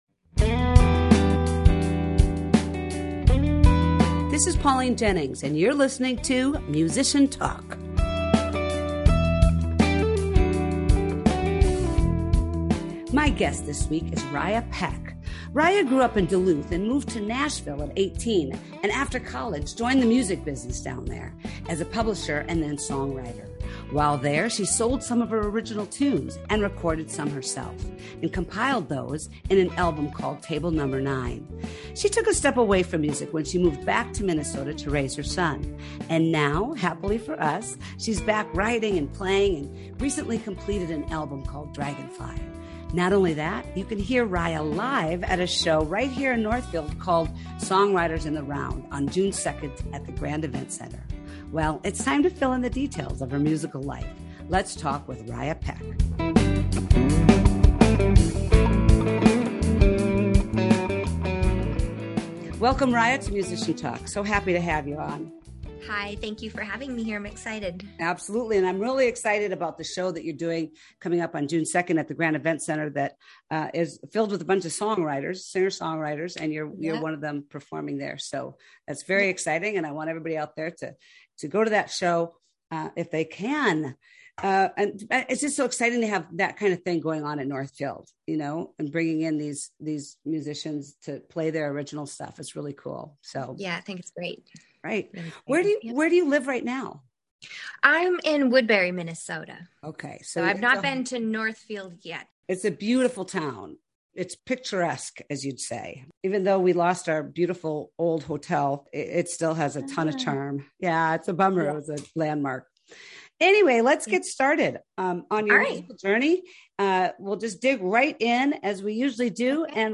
Listen to a few of her tunes hot off the press, her best and worst gigs, and more.